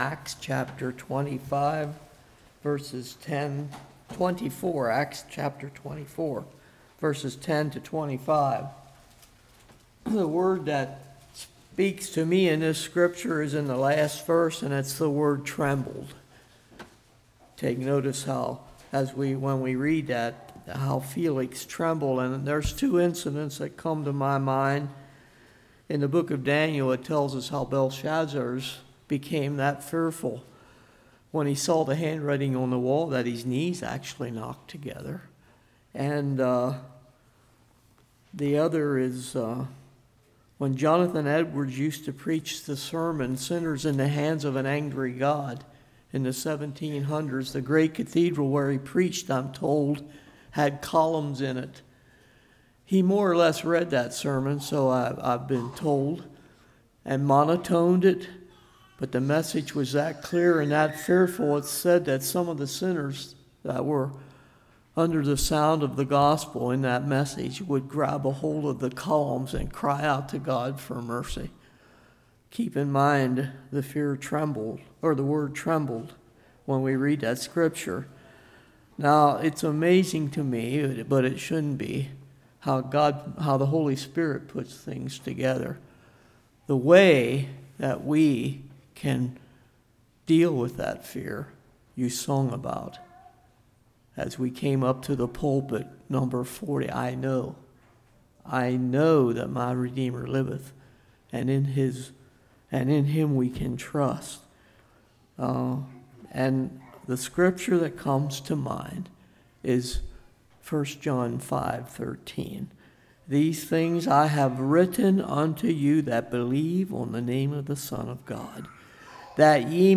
Service Type: Morning